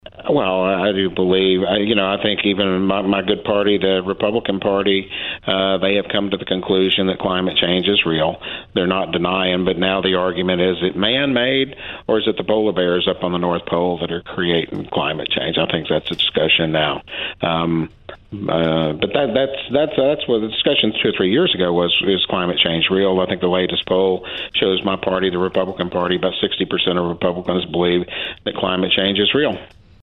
Dale-Ross-Mayor-Georgetown-TX.mp3